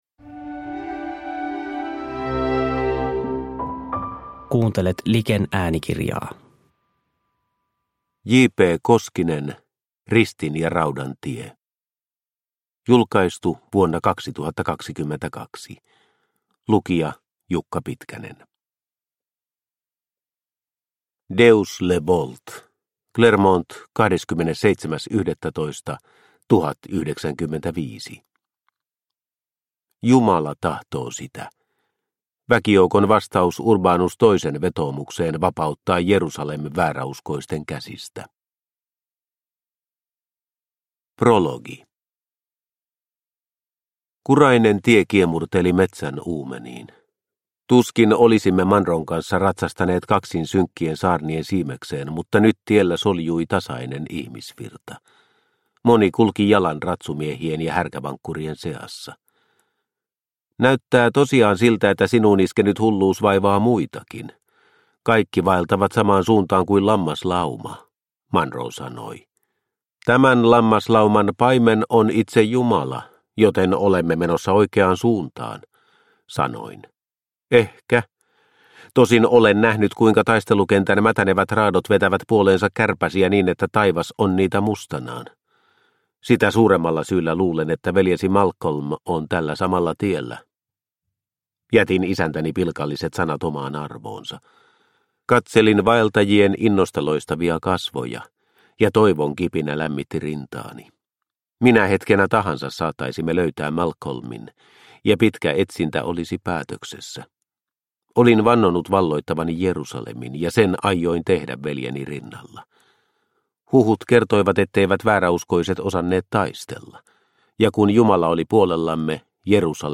Ristin ja raudan tie – Ljudbok – Laddas ner